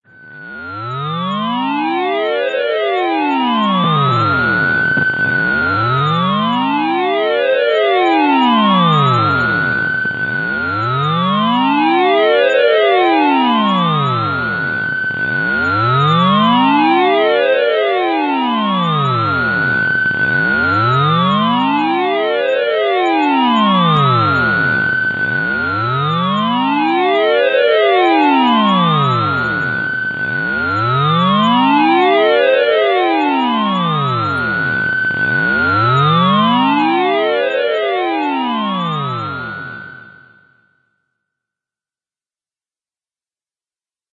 科幻警报
标签： 未来 太空飞船 电子 激光 数字 科幻 未来 太空战 信号 外星人 科幻 空间 报警 危险 科幻 计算机 警报器
声道立体声